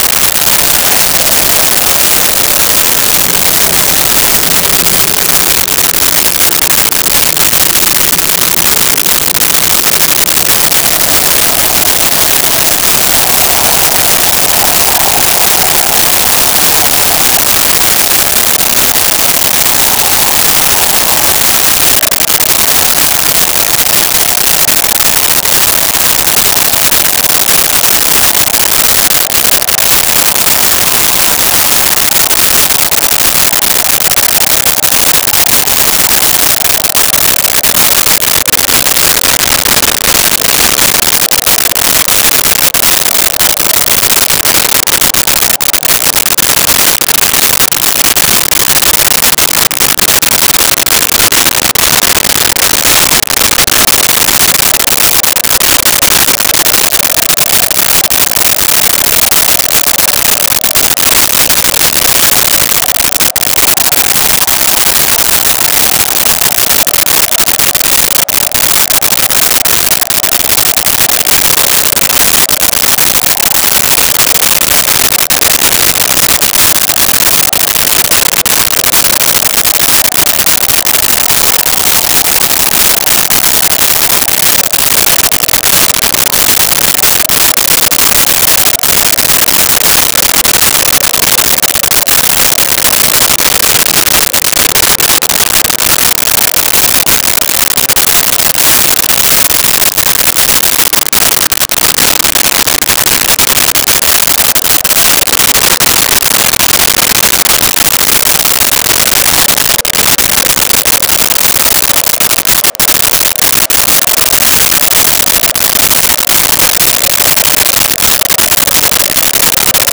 Wind Medium
Wind Medium.wav